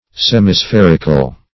Search Result for " semispherical" : The Collaborative International Dictionary of English v.0.48: Semispheric \Sem`i*spher"ic\, Semispherical \Sem`i*spher"ic*al\, a. Having the figure of a half sphere.